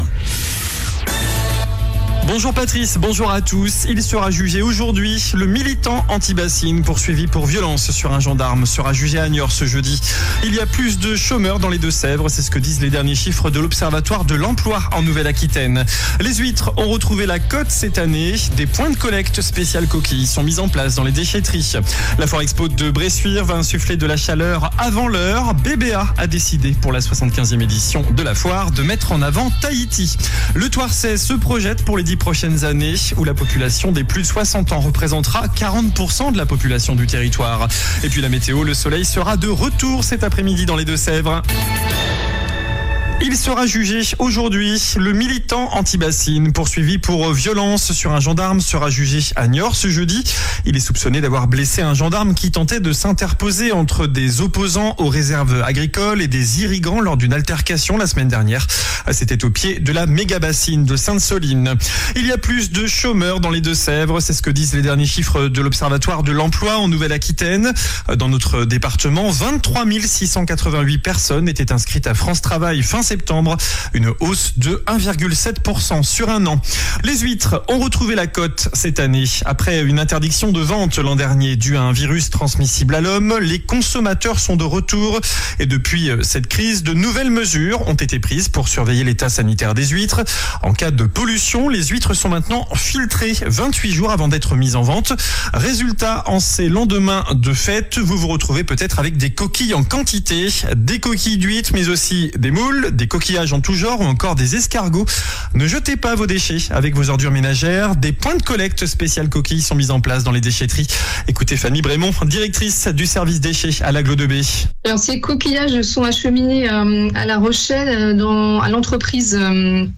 JOURNAL DU JEUDI 26 DECEMBRE ( MIDI )